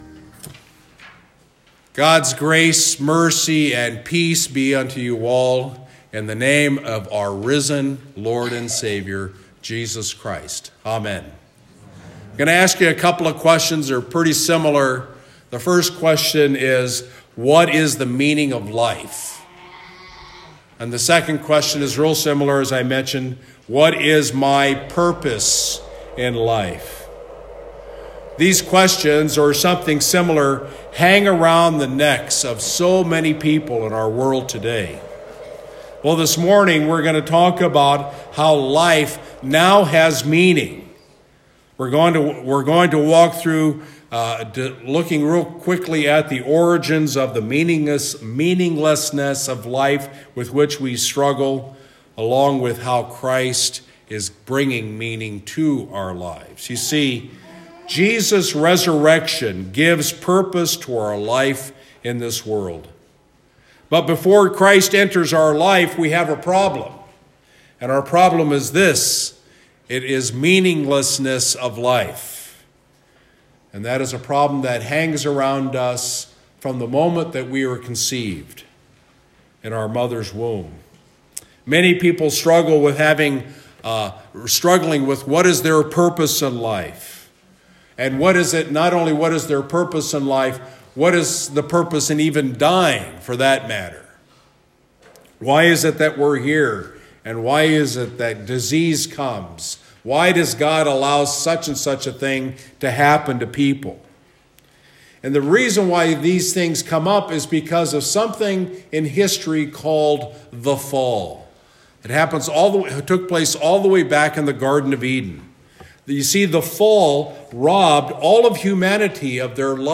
“Life Now Has Meaning” — Sermon for Easter Sunday
Easter Sunday Message from Christ Lutheran Church of Chippewa Falls, WI